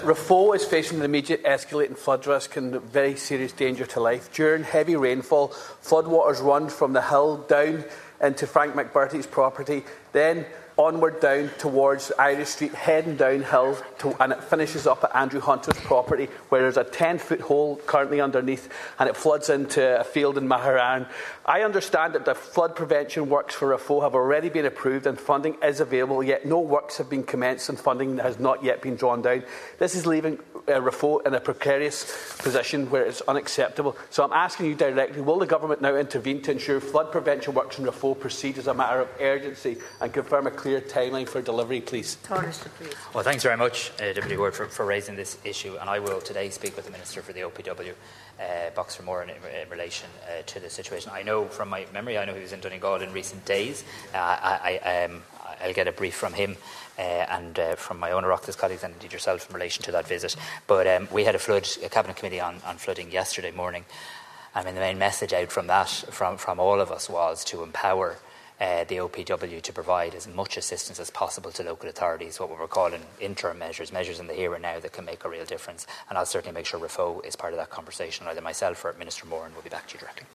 Speaking in the Dáil earlier this week, Deputy Charles Ward outlined how the flood water from the hills above Raphoe channels through the town and has now created a gaping hole over ten feet deep beside critical sewer infrastructure on Railway Road.